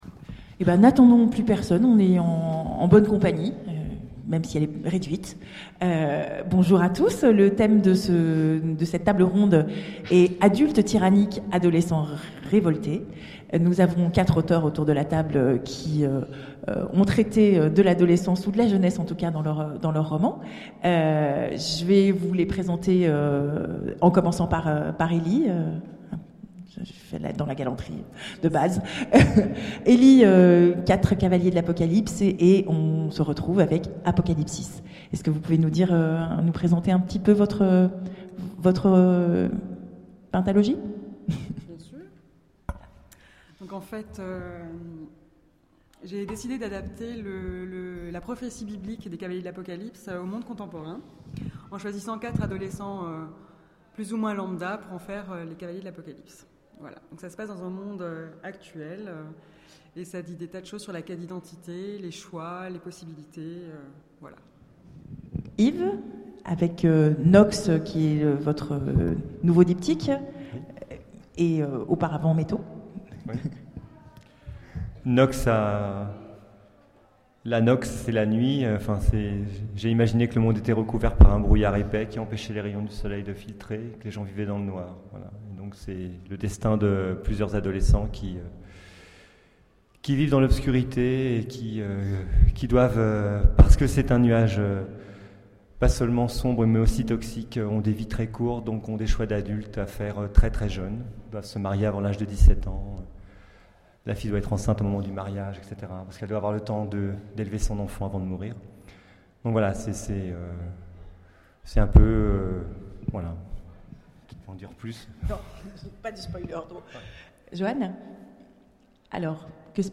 Imaginales 2013 : Conférence Adultes tyranniques...